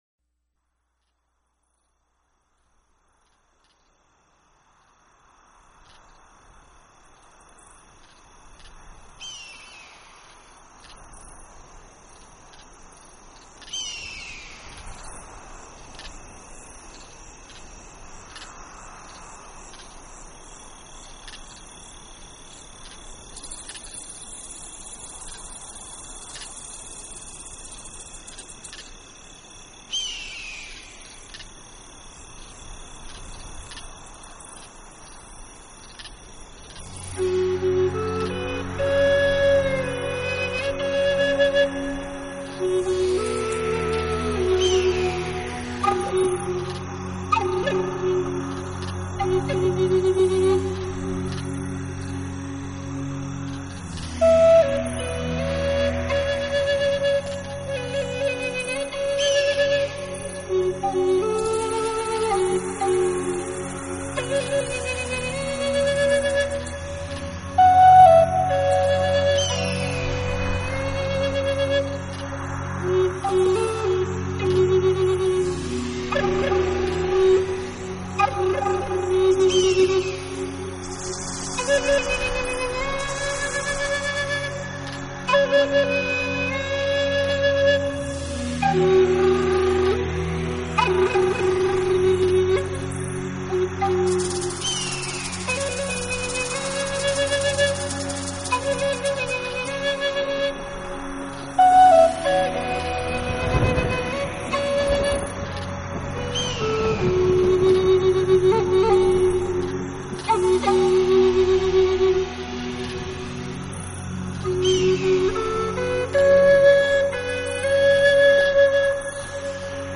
Style: World, Native American
Native American flute music